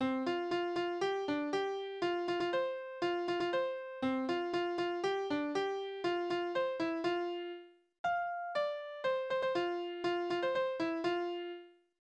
Berufslieder: Pfannenflicker
Tonart: F-Dur
Taktart: 4/8
Tonumfang: Oktave, Quarte